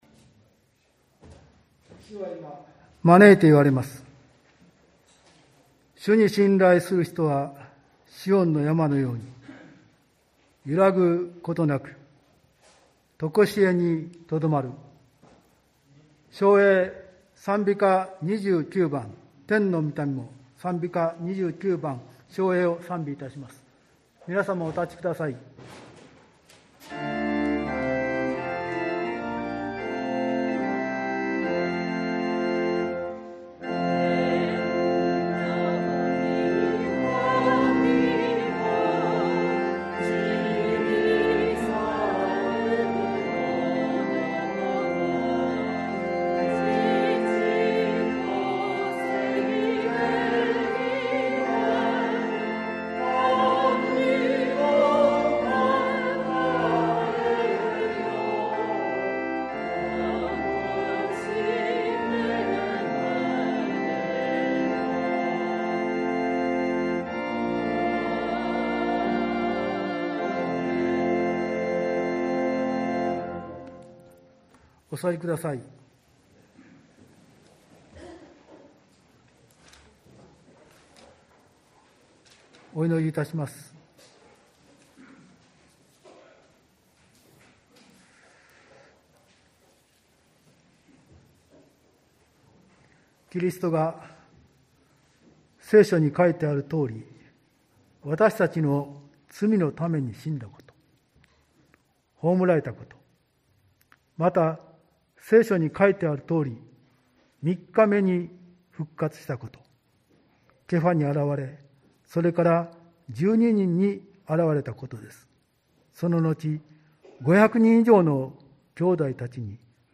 礼拝音源(30.4MB)